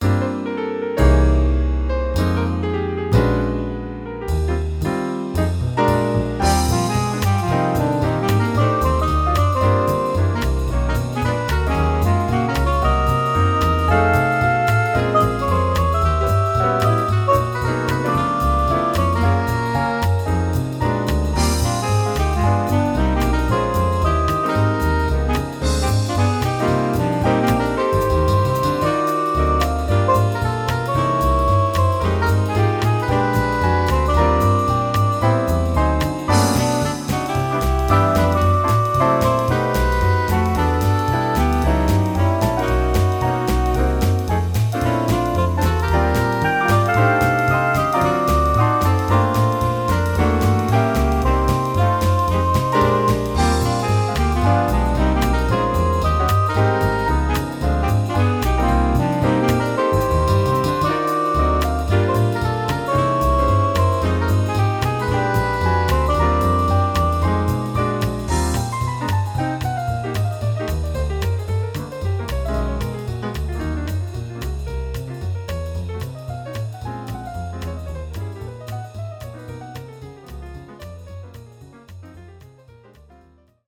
Here are demo recordings I did at home of ten original pieces for jazz quintet. These are all short clips (1-2 minutes); the head with a chorus or two of piano solo.